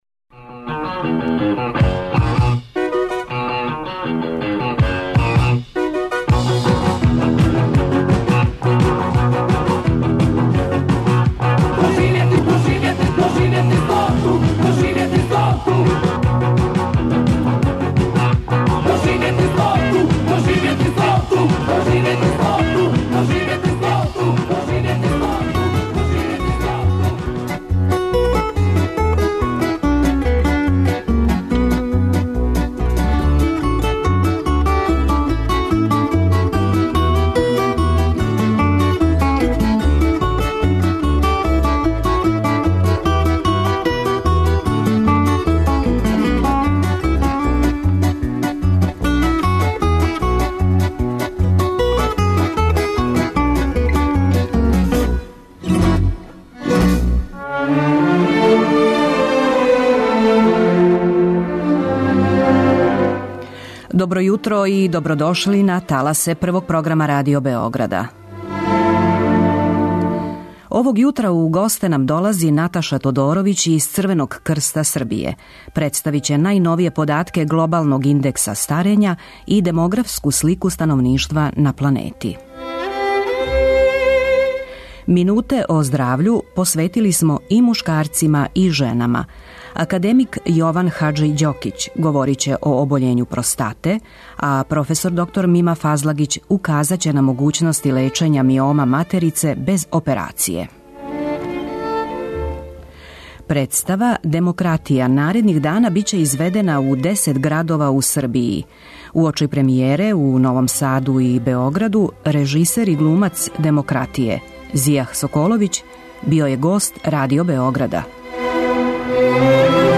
Емисија "Доживети стоту" Првог програма Радио Београда доноси интервјуе и репортаже посвећене старијој популацији.